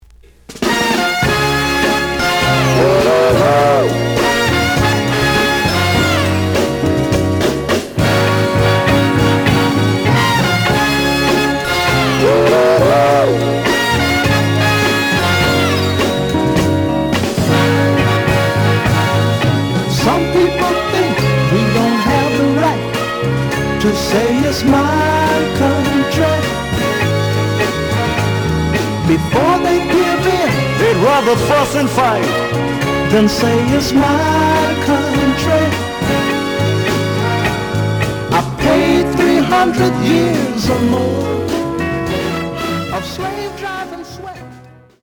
The audio sample is recorded from the actual item.
●Format: 7 inch
●Genre: Soul, 60's Soul
Looks good, but slight noise on both sides.)